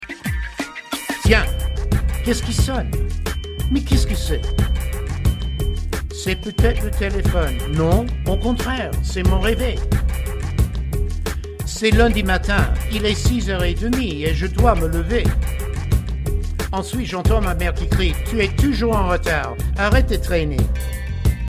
French Raps Song Lyrics and Sound Clip